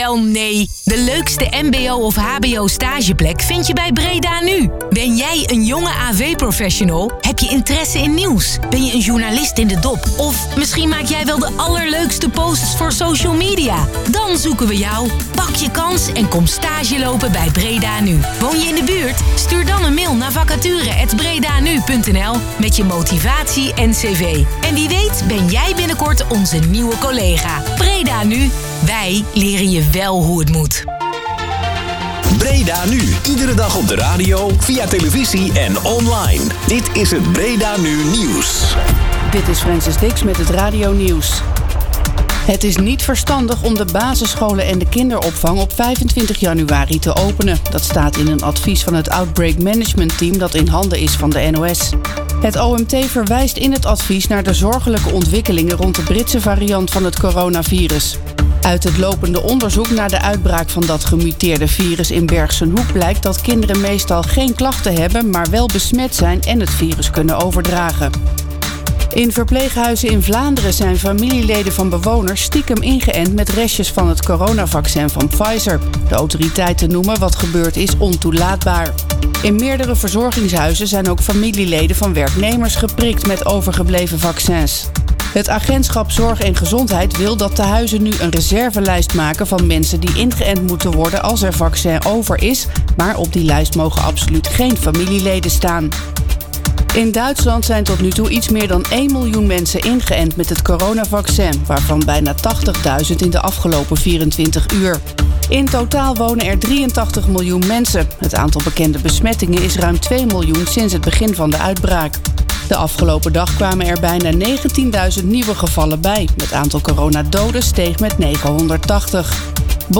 De uitzending
Ik was uitgenodigd in de studio van De Avondmatties, programma van lokale omroep BredaNu.